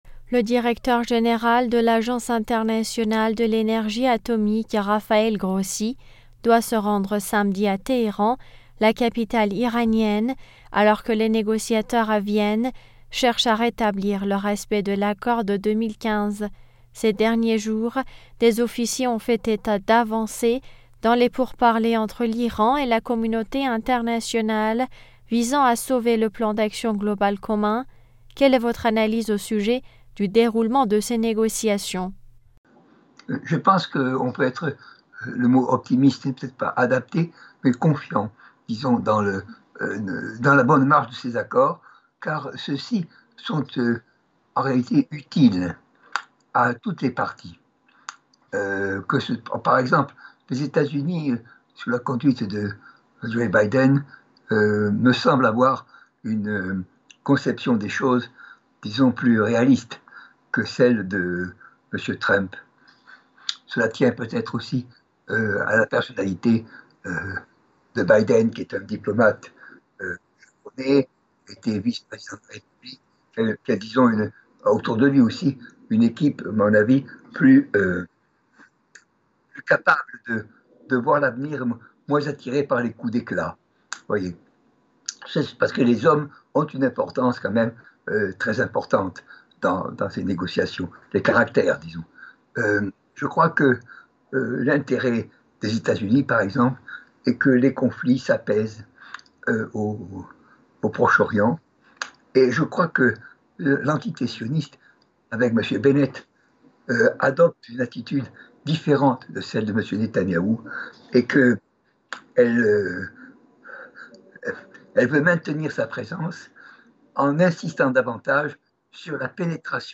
politologue s’exprime sur le sujet.